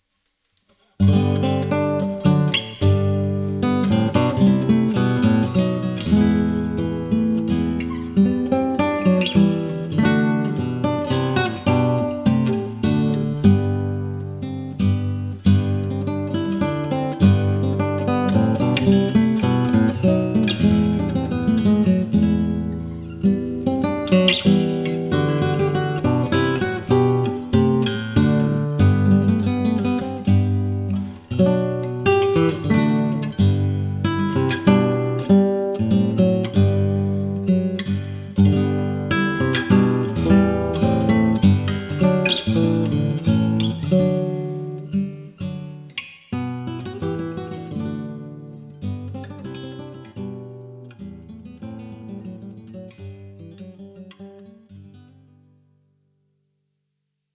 Die Konzertgitarre wird auch klassische oder spanische Gitarre genannt.
Die Konzertgitarre ist die ideale Gitarre für Anfänger, da sie mit Nylonsaiten bespannt ist.
Die Konzertgitarre wird hauptsächlich mit den Fingern gespielt, nur sehr selten mit einem Plektrum.